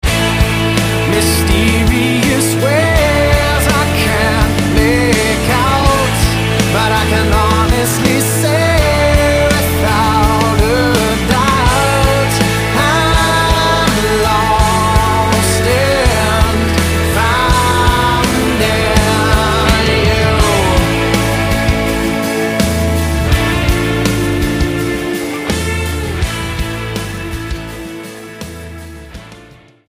STYLE: Rock
emotional ballad